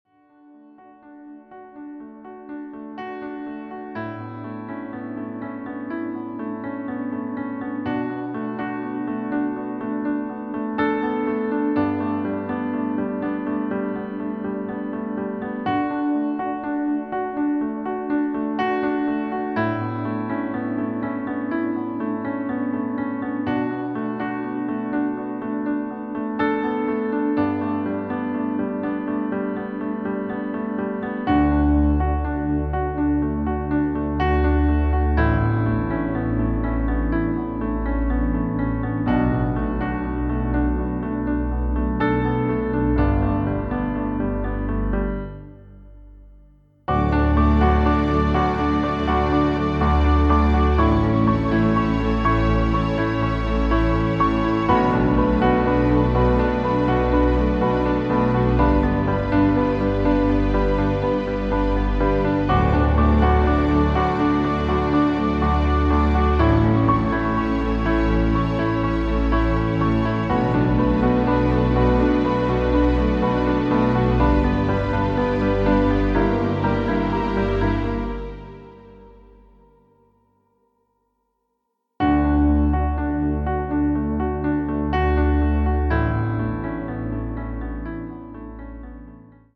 • Das Instrumental beinhaltet NICHT die Leadstimme
Klavier / Streicher